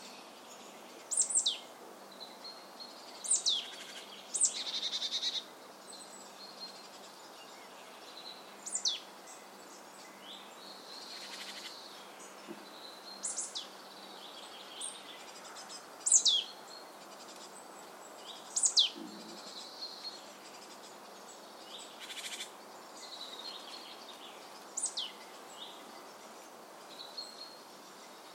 Chestnut-backed Chickadee